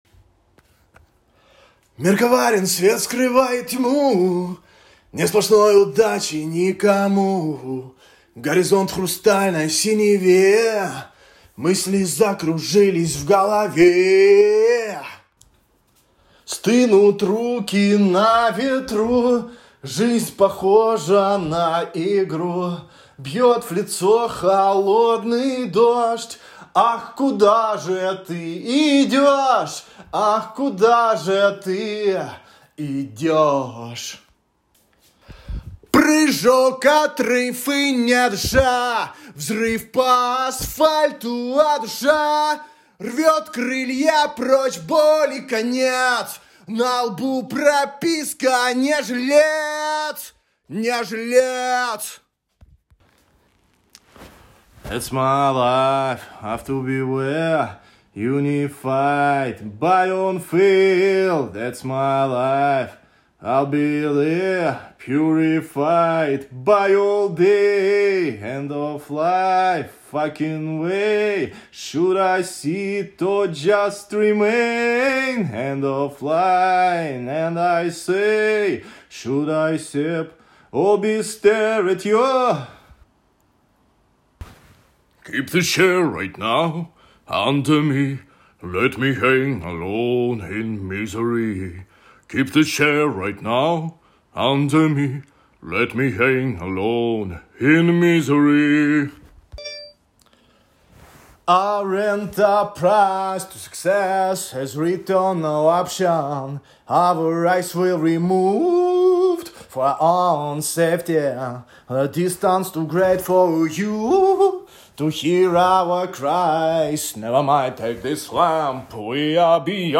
Я щас выложу запись на диктофон, где много кусочков песен в разных манерах.
Сначала в файле идет а'ля-попсишка с предыханием, дальше забавные вариации гусиного тенора/баритона (есть и конченные фейлы, не по Сеньке шапка)...